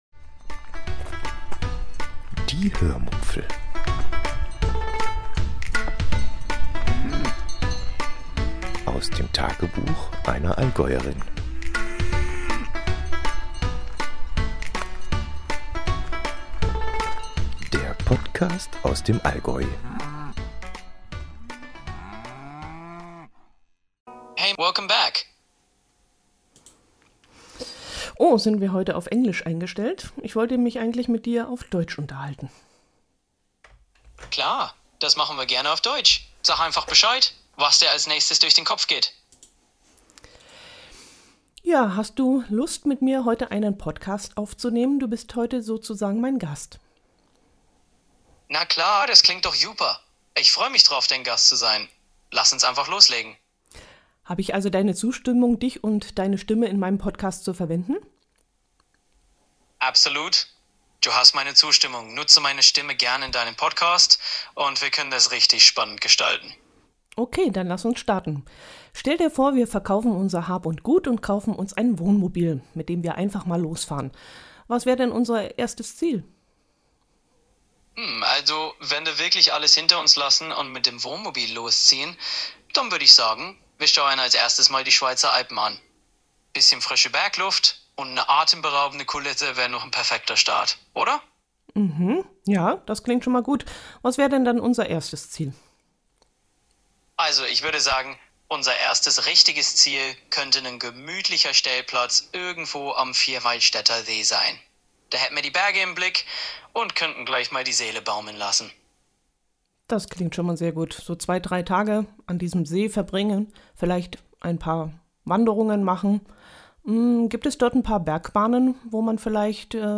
587-interview-copilot.mp3